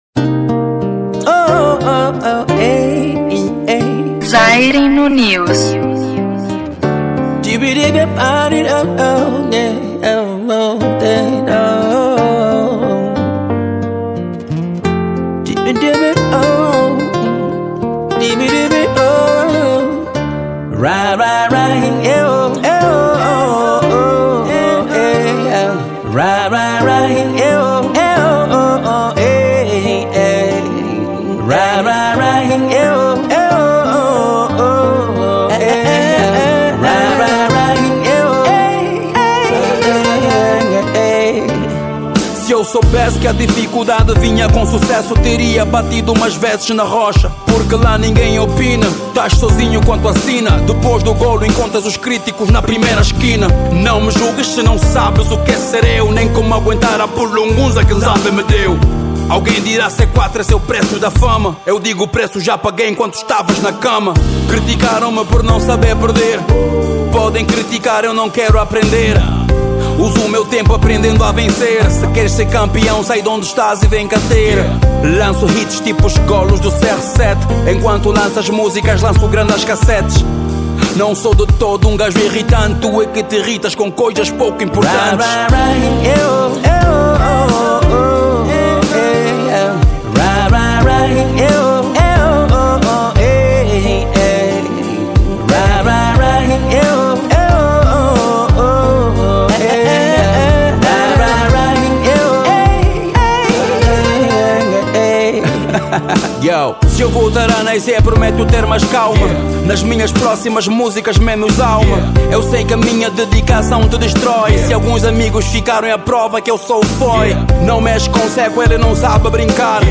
Estilo: Afro pop